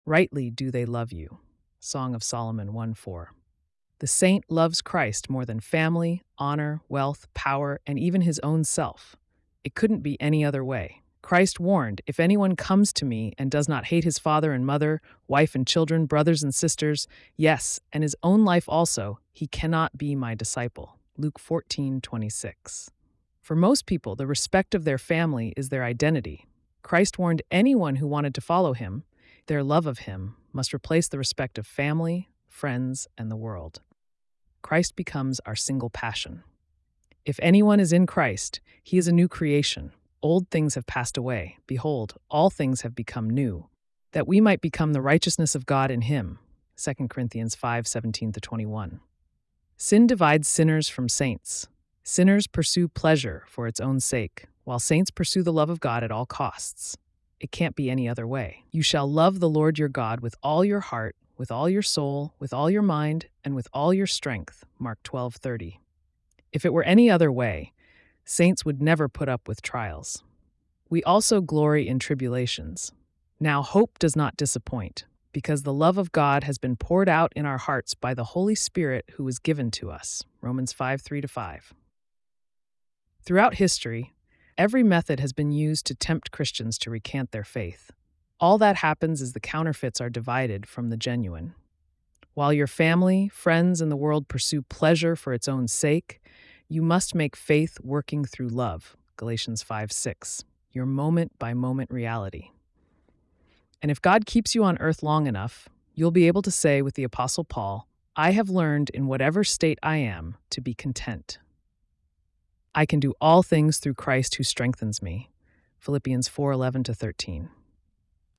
August 7 Morning Devotion